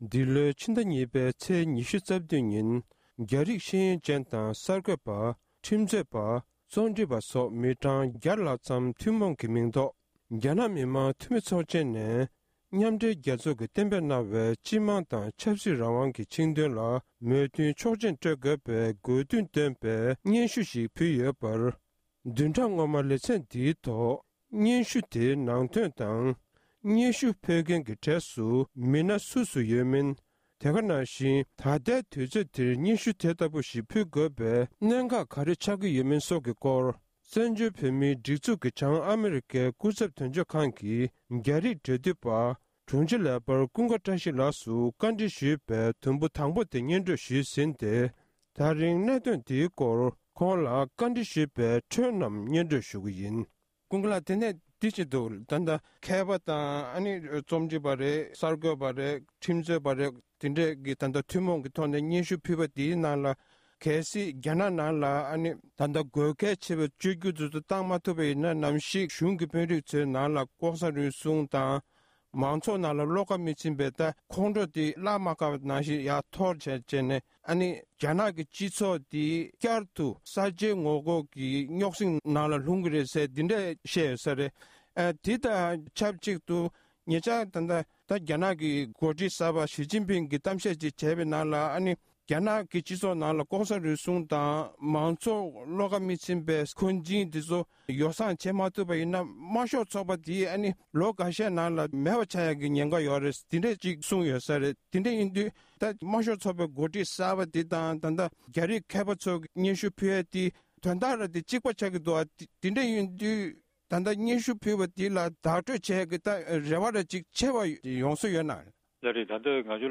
གནས་འདྲི་ཞུས་པར་མུ་མཐུད་ནས་གསན་རོགས༎